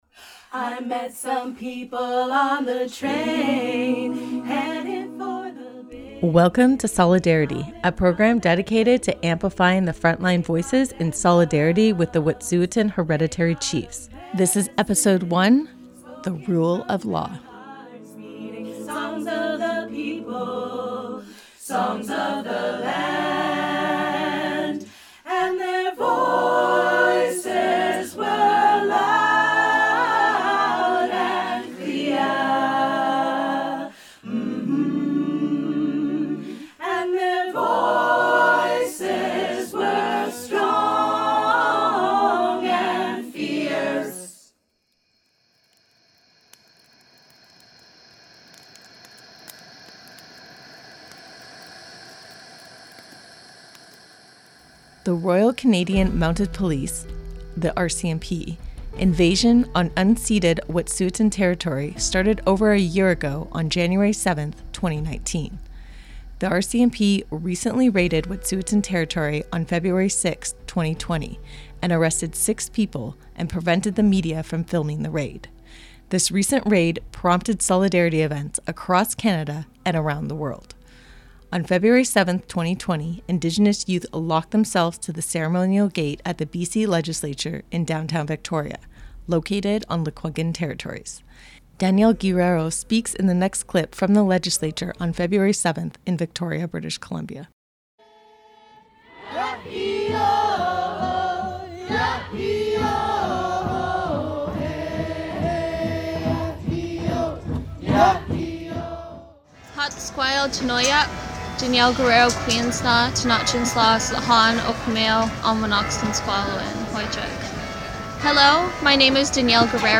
CFUV producers interview Indigenous youth occupying Victoria's legislature building